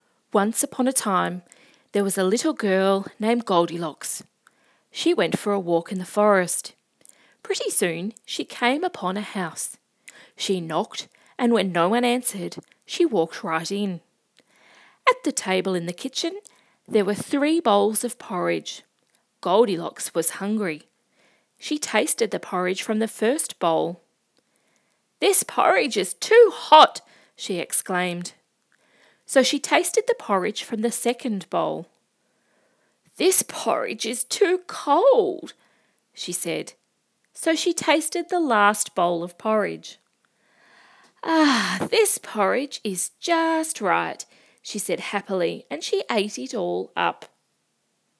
Let’s work through a short example…..it’s a rudimentary example using the familiar story of Goldilocks and the Three Bears.